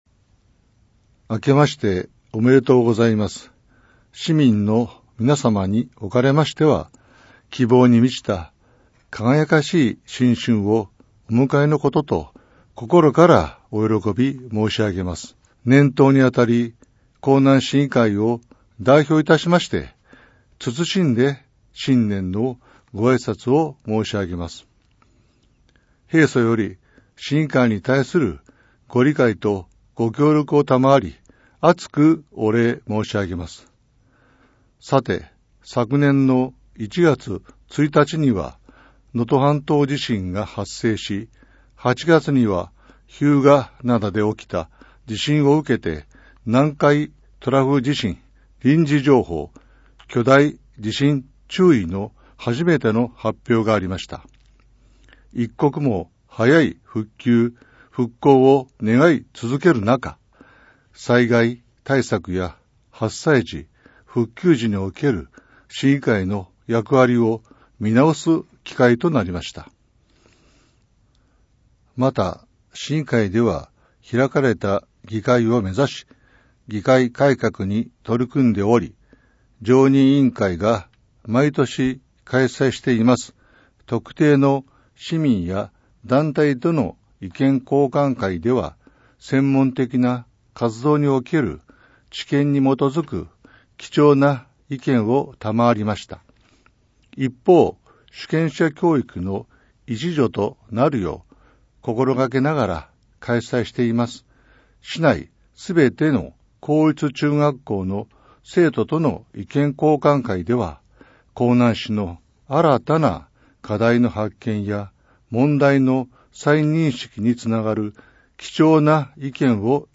江南市議会議長 伊藤 吉弘 議長新年あいさつ音源ダウンロード （mp3 642.0KB） ※録音に際しては、声のボランティア 「やまびこ」の方々にご協力いただきました。